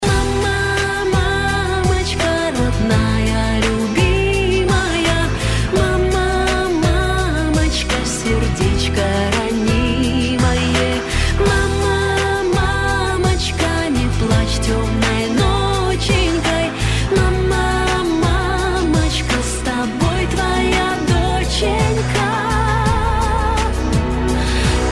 Медленные , Душевные
Поп